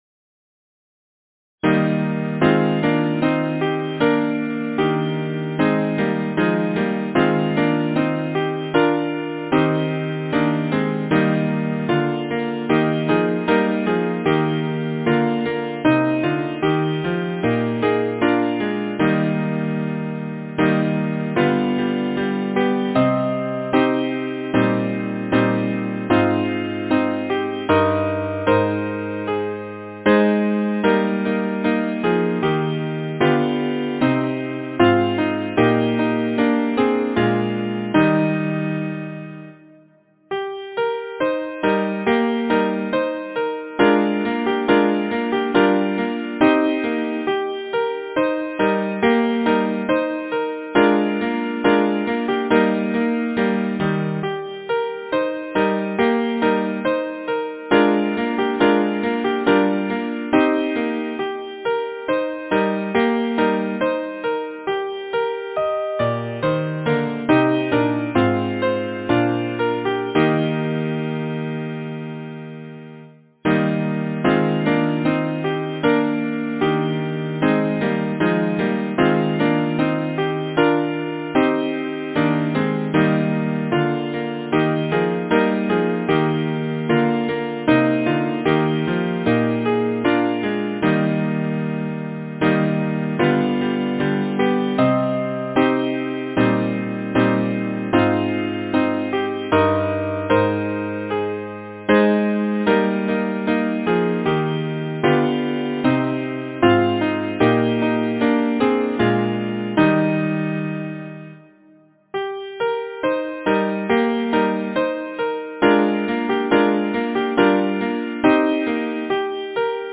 Number of voices: 4vv Voicing: SATB Genre: Secular, Partsong, Folksong
Language: English Instruments: A cappella
Scottish cradle song